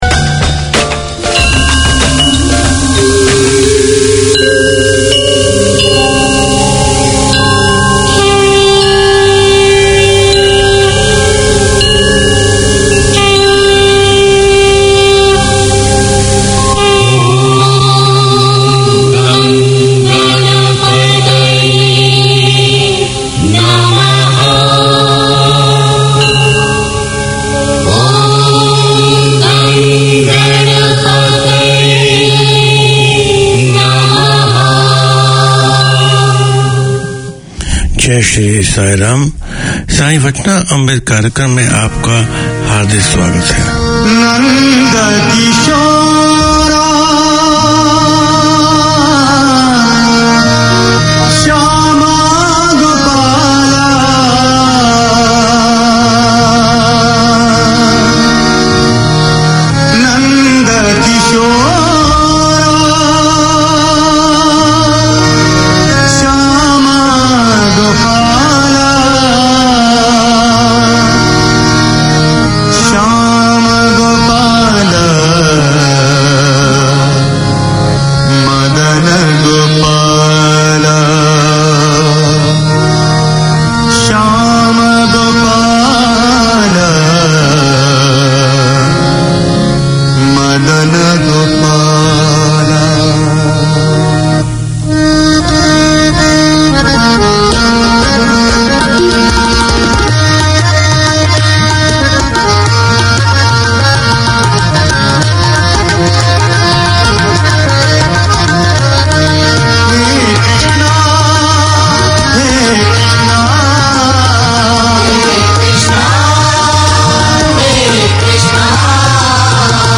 Community Access Radio in your language - available for download five minutes after broadcast.
The programme showcases the history, traditions and festivals of India and Fiji through storytelling and music, including rare Fiji Indian songs.